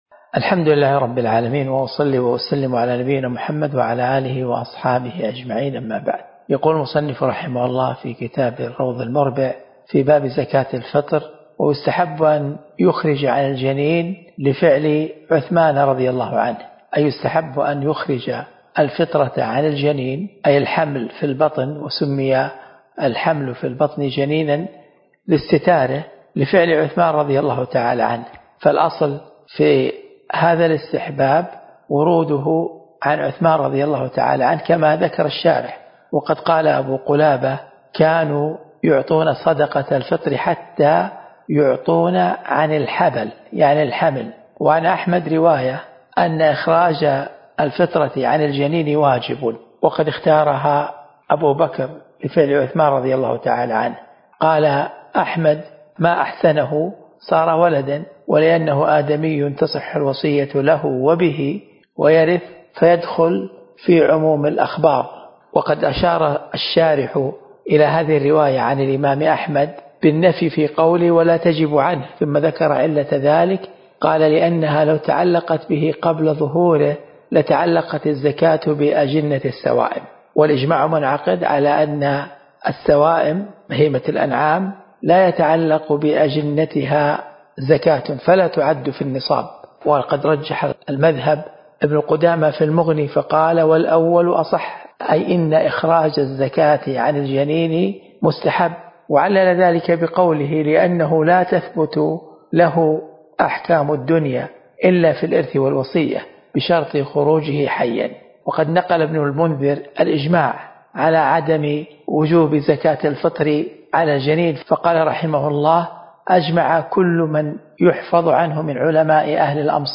الدرس (14) من شرح كتاب الزكاة من الروض المربع